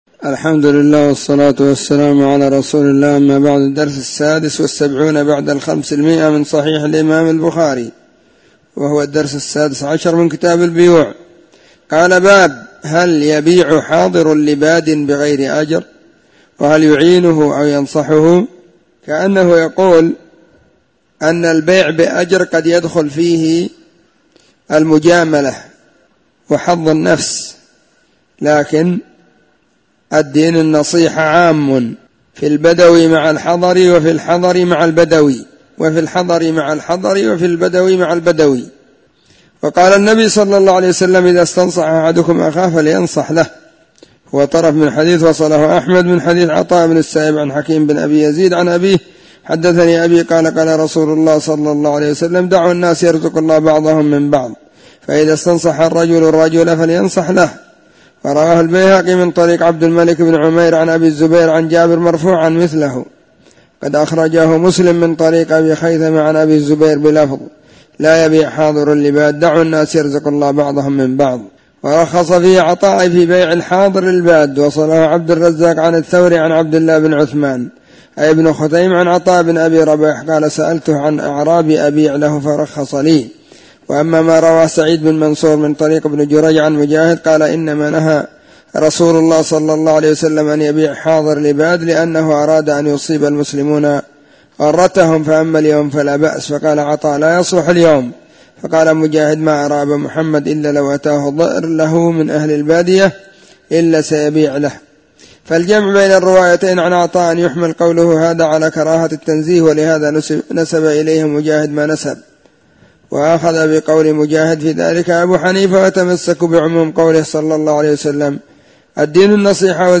🕐 [بين مغرب وعشاء – الدرس الثاني]